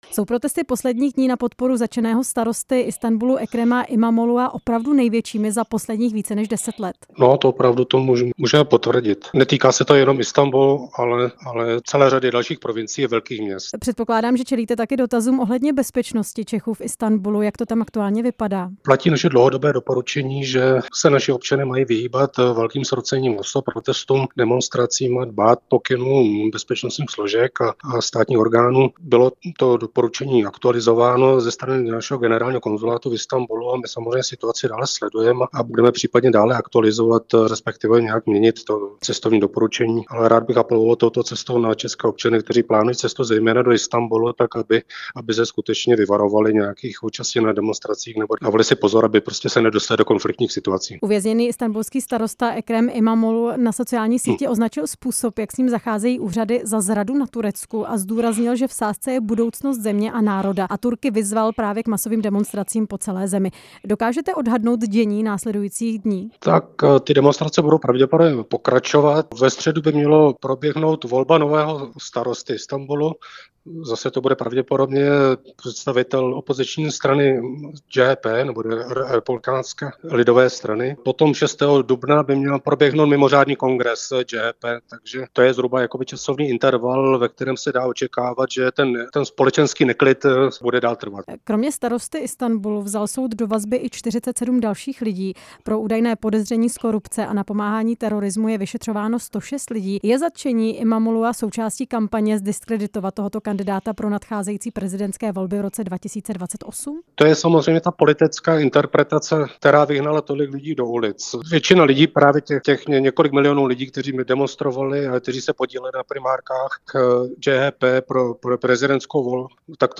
Dění v zemi jsme ve vysílání Rádia Prostor rozebírali s velvyslancem v Turecku Petrem Štěpánkem.
Rozhovor s velvyslancem v Turecku Petrem Štěpánkem